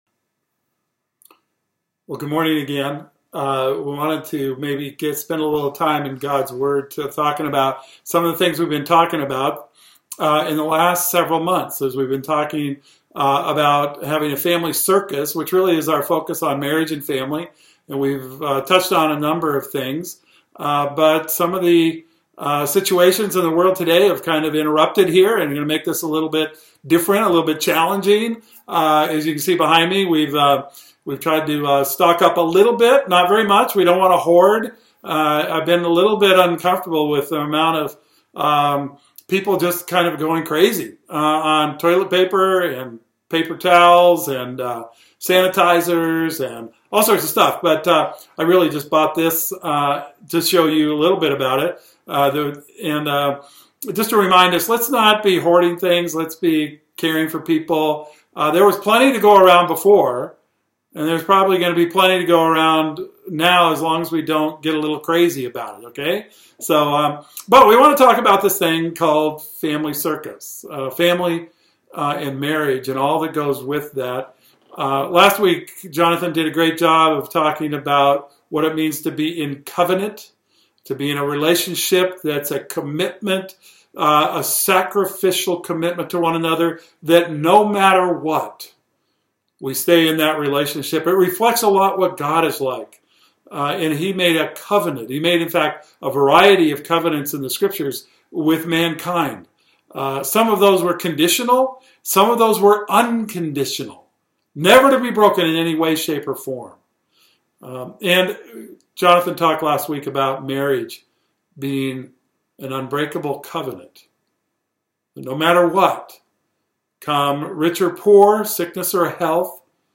Today's message is only an online message due to cancelling our large group gatherings like Sunday morning service over COVID-19 concerns. Today's message continues our Family Circus series and dives into the idea of intimacy and marriage.